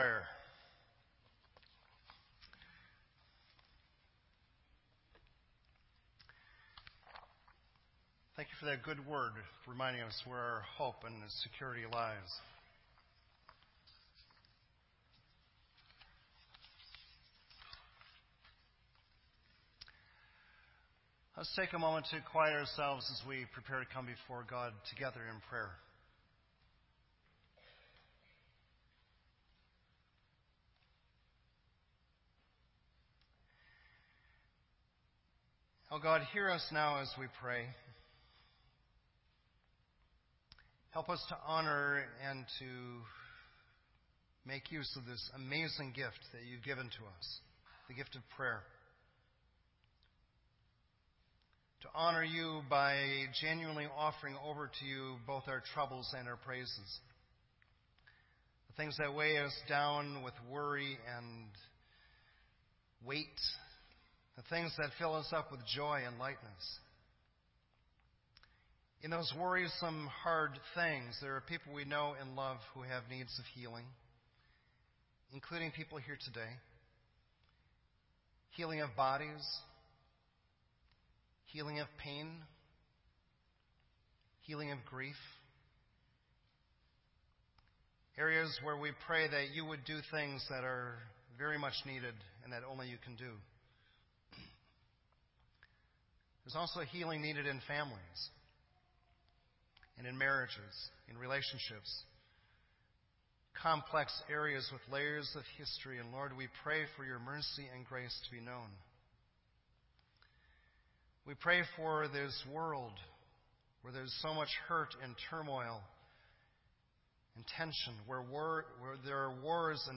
This entry was posted in Sermon Audio on October 31